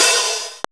CYM XCHEEZ0A.wav